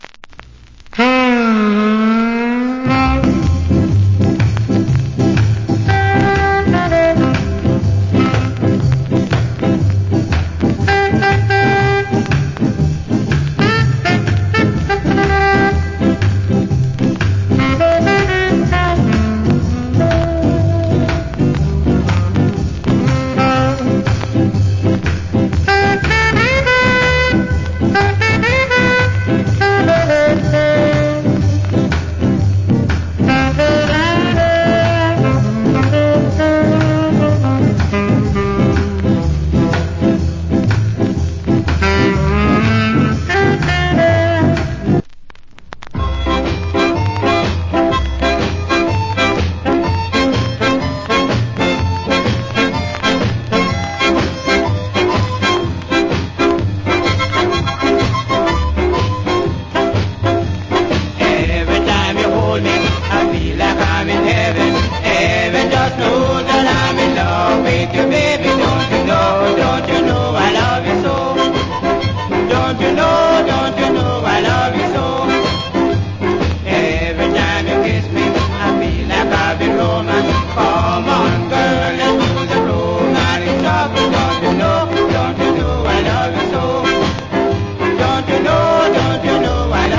Big Shot Shuffle Iinst.